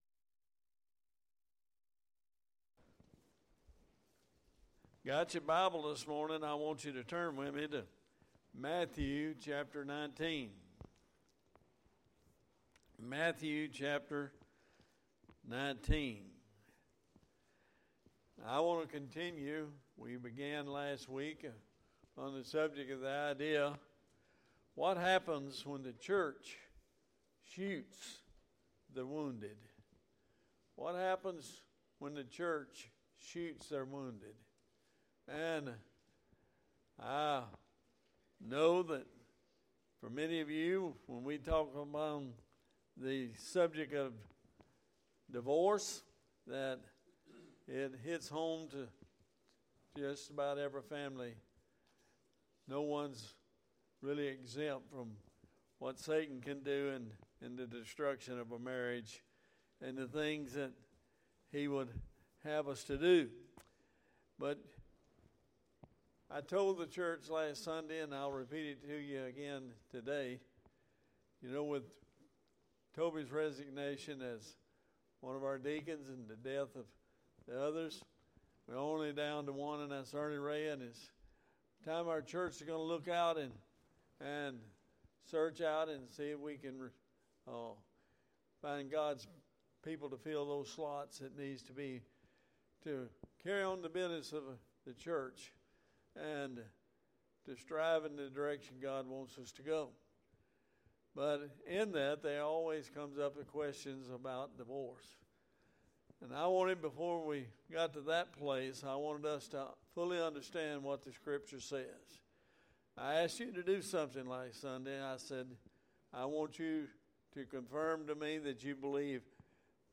Part 3 AM Sermon Qualified Servants 1 Corinthians 7:1-15